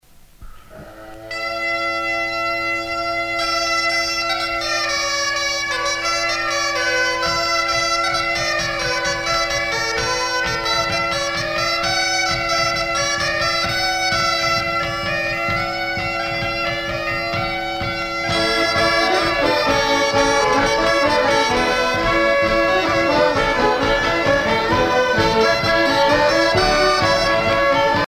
danse : bal (Bretagne)
Pièce musicale éditée